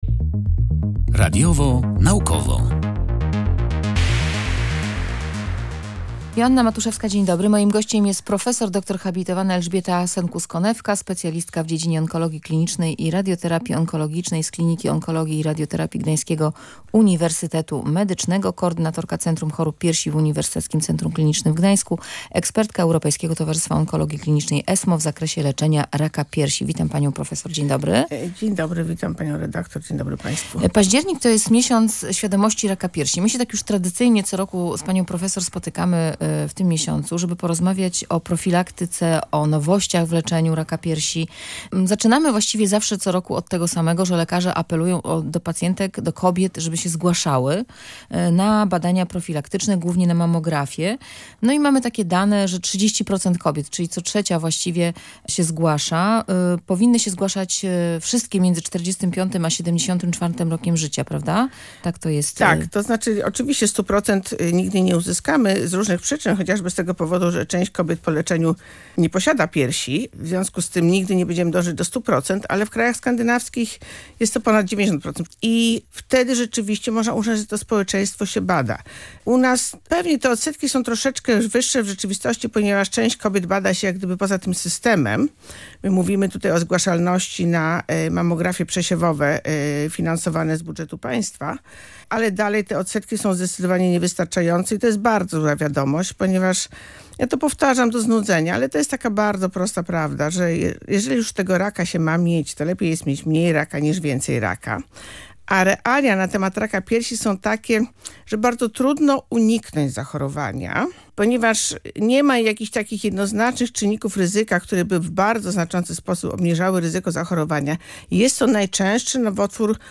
Październik jest miesiącem świadomości raka piersi. To dobra okazja do rozmów o profilaktyce i nowościach w leczeniu.